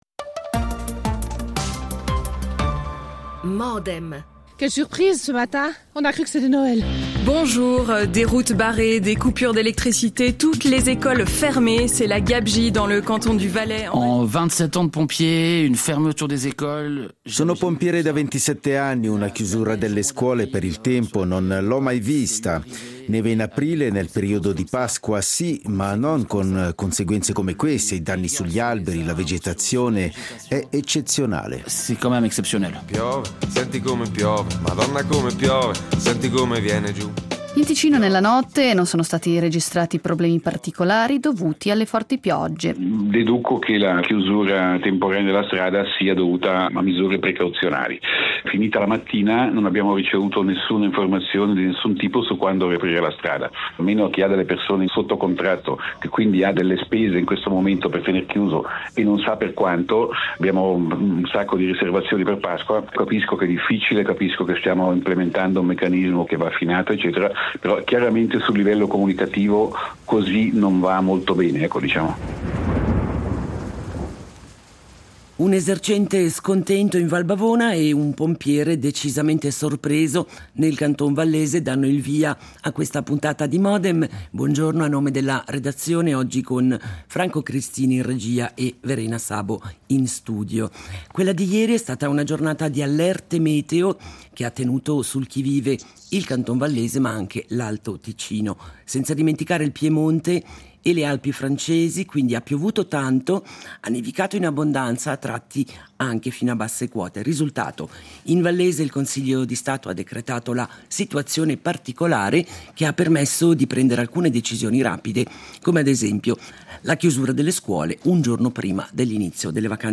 Allerte, giuste misure, danni di immagine e prospettive per la stagione turistica nella nostra discussione con (non tutti insieme):
L'attualità approfondita, in diretta, tutte le mattine, da lunedì a venerdì